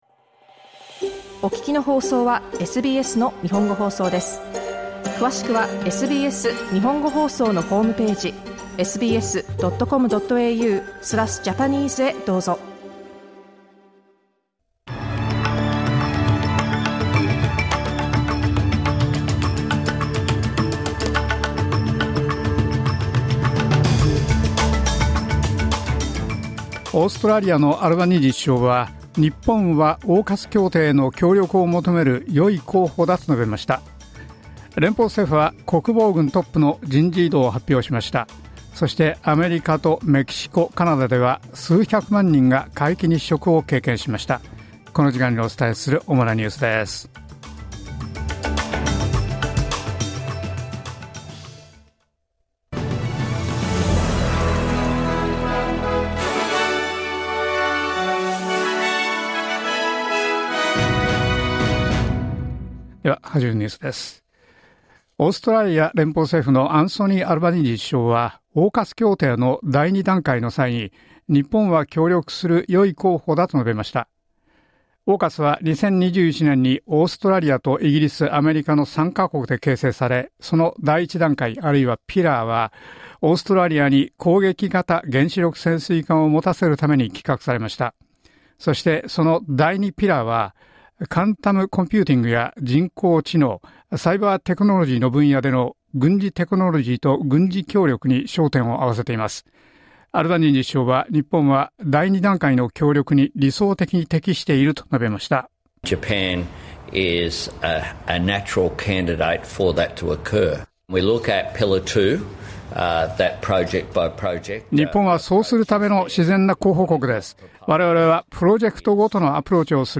SBS日本語放送ニュース４月９日火曜日